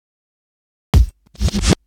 Fill 128 BPM (7).wav